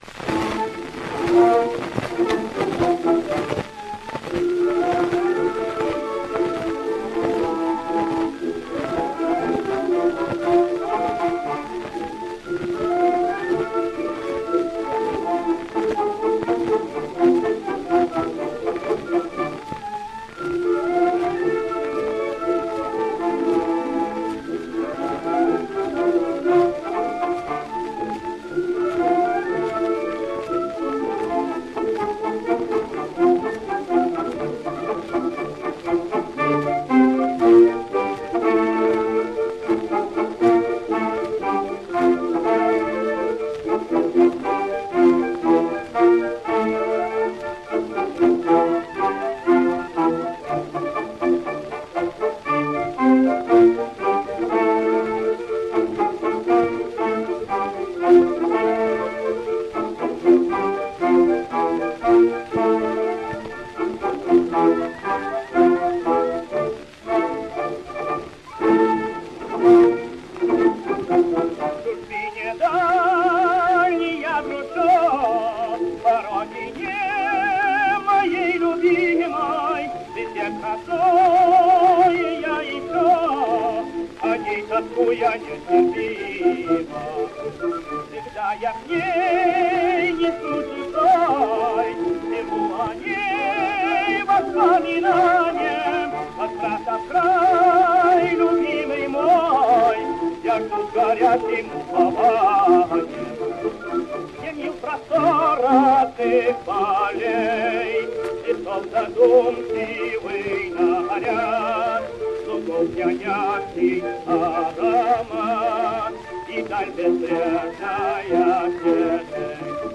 Она была сделана в Нью-Йорке в мае 1921 года.
Песня «Тоска по Родине» на слова Альб.